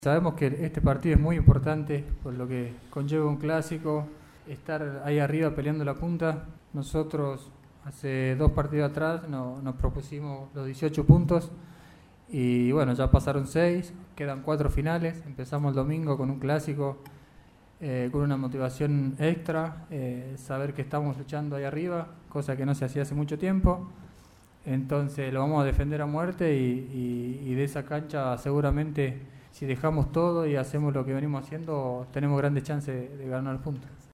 En conferencia de prensa, Rodríguez sostuvo que “vamos a ir a buscar la Copa. Estamos motivados, con mucha confianza y sabemos que venimos levantando. Vamos a luchar hasta el final, buscar los 12 puntos y después se verá si se nos da o no”.